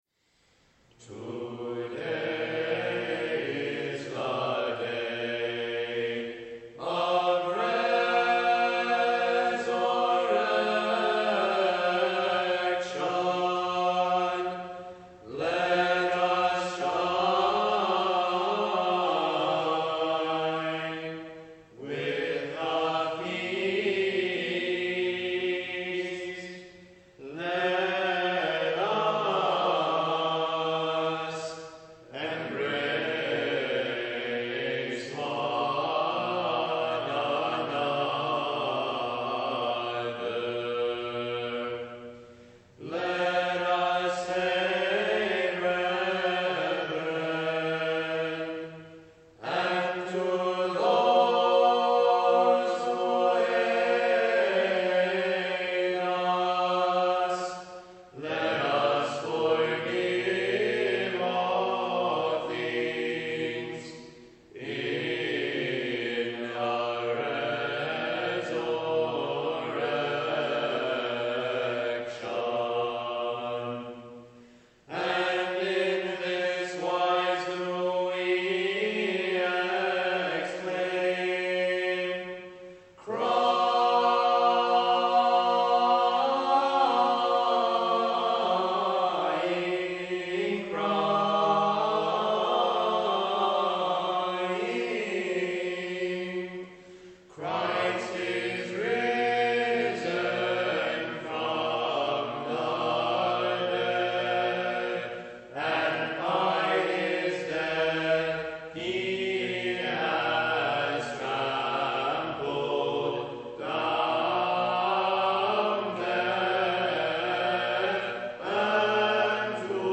Recordings of our Byzantine Choir
Live Recordings from Services